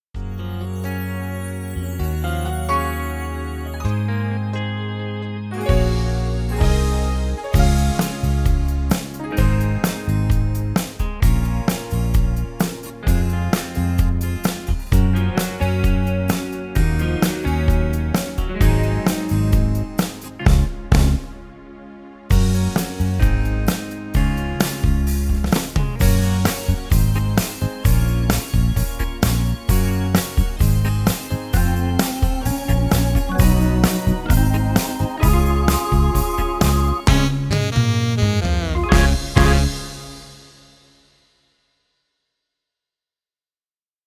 Intro/endings works in Major.
Akkordforløb i introer og endings fungerer i dur akkorder.